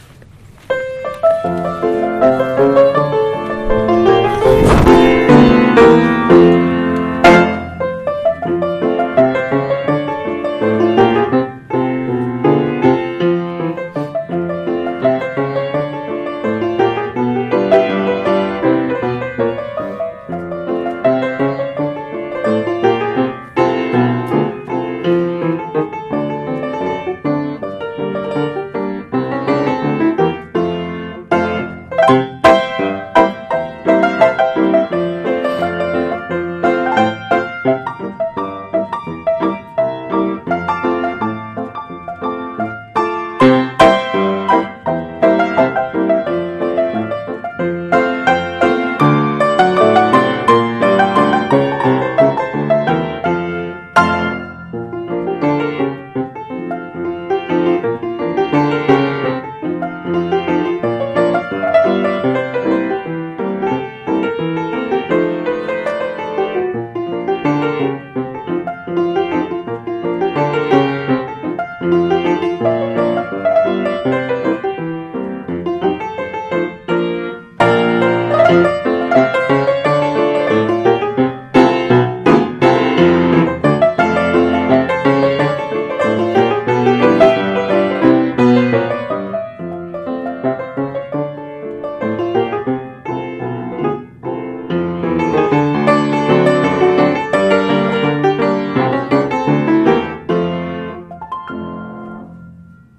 POSTLUDE -  Why We Smile  - Charles Hunter